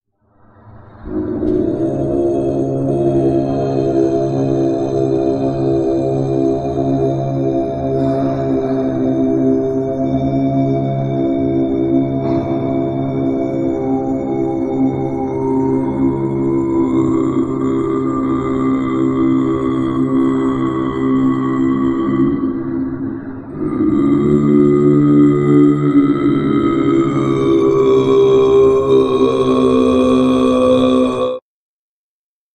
Polyphonie in der Stimme
Untertöne sind Schwingungen, die unterhalb der normalen Singstimme hörbar mitschwingen.
Untertongesang mit Obertönen kombiniert
polyphones-singen.mp3